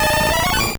Cri d'Aéromite dans Pokémon Or et Argent.